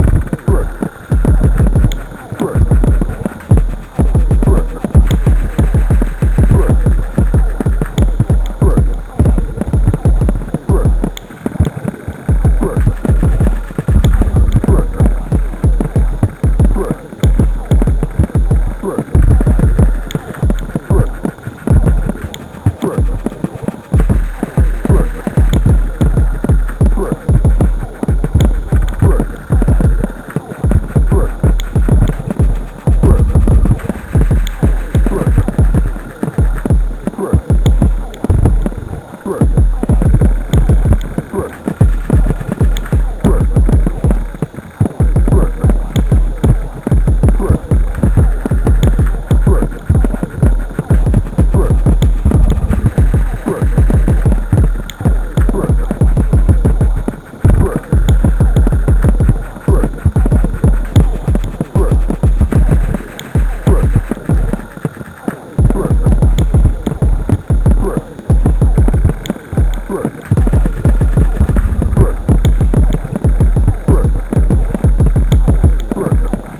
various different samples of my voice using only an SK1 recorded into Ableton. Most of the rhythmic variation is a result of the sample loop function on the SK.